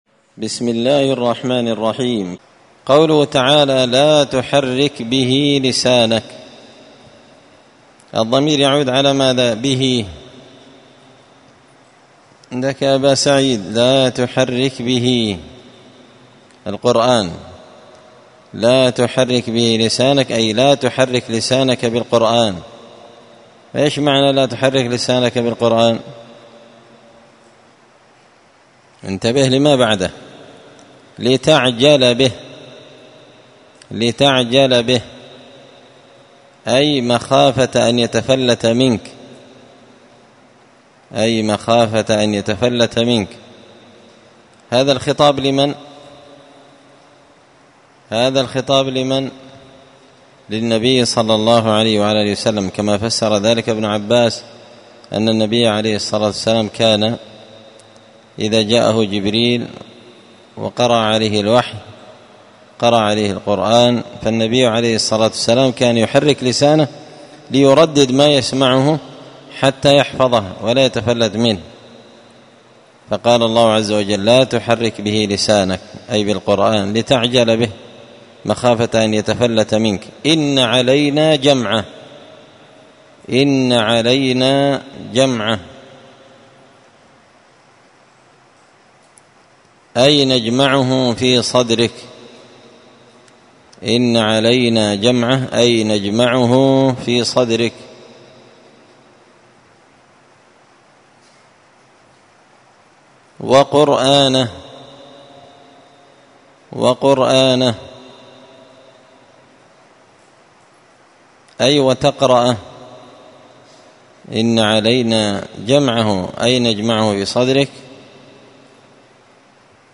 (جزء تبارك سورة القيامة الدرس 104)